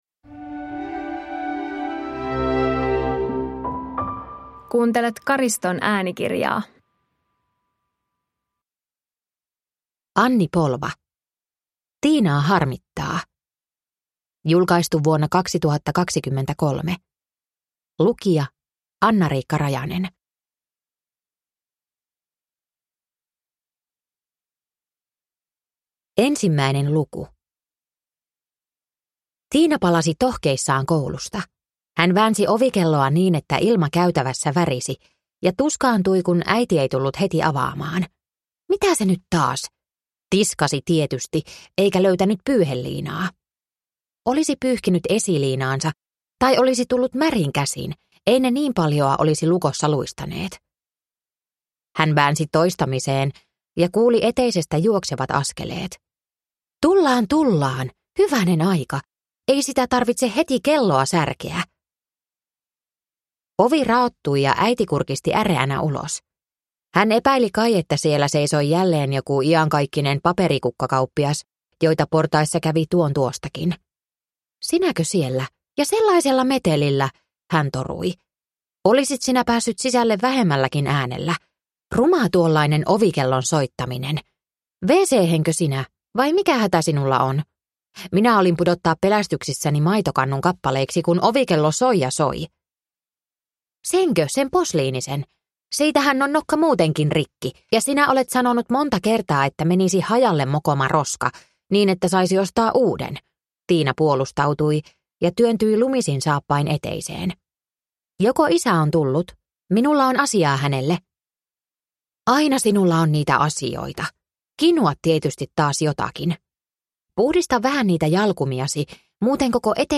Tiinaa harmittaa – Ljudbok – Laddas ner